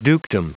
Prononciation du mot dukedom en anglais (fichier audio)
Prononciation du mot : dukedom